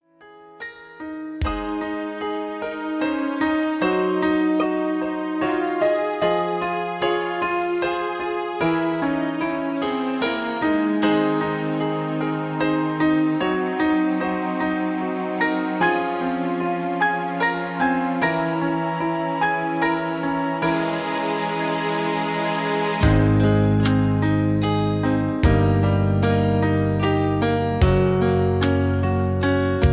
Key-Am) Karaoke MP3 Backing Tracks
Just Plain & Simply "GREAT MUSIC" (No Lyrics).